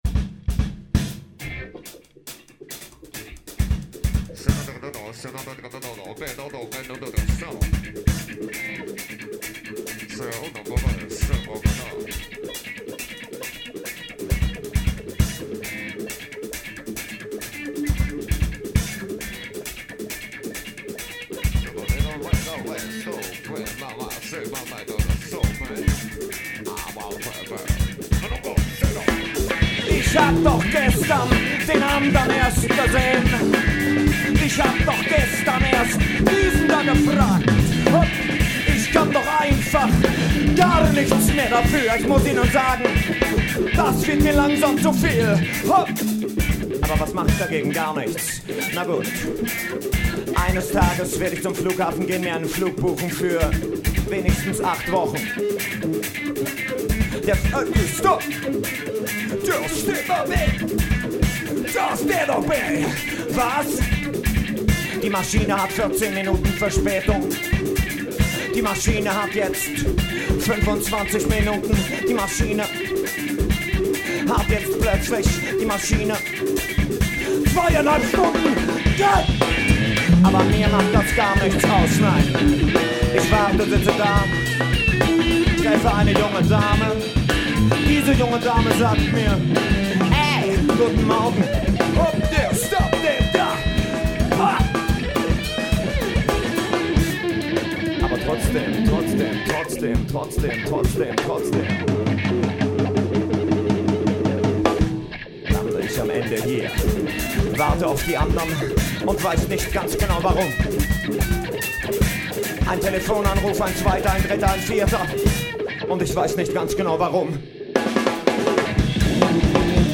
KONZERTMITSCHNITT